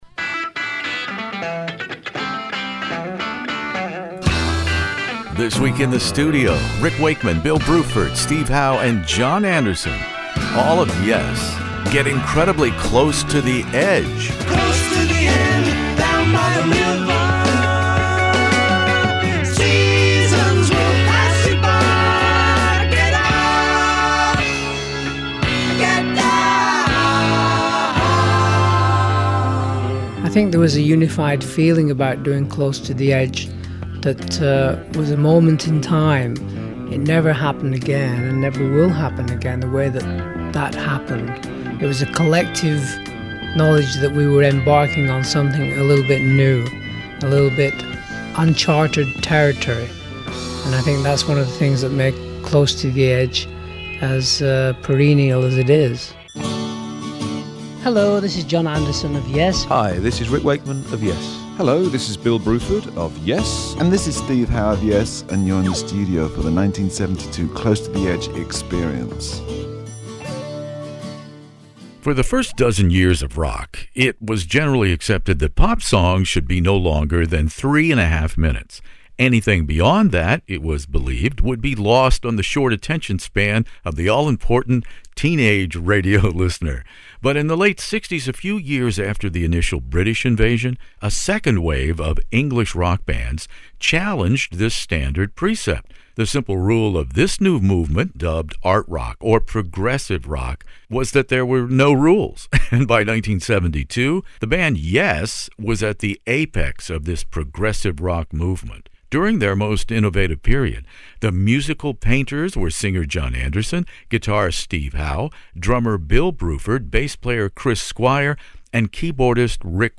YES "Close to the Edge" rare interview with Jon Anderson, Steve Howe, Rick Wakeman, Bill Bruford In the Studio for 1972 Progressive Rock peak